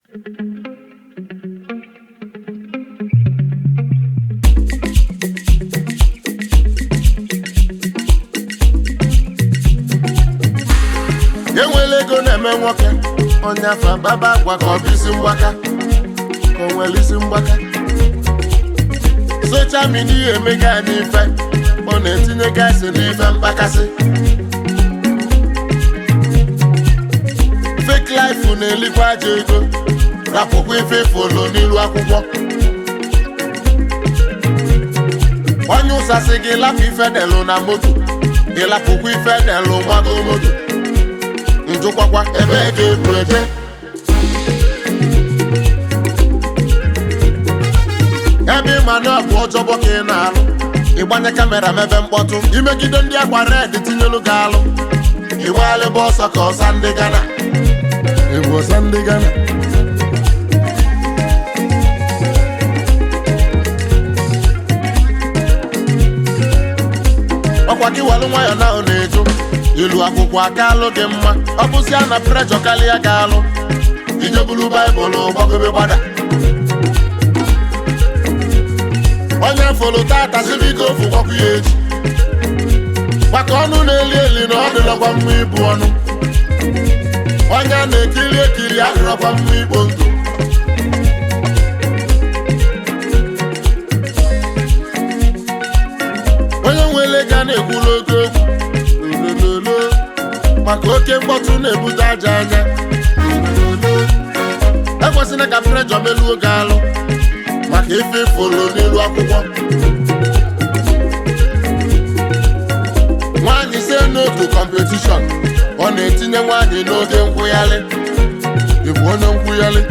a good highlife tune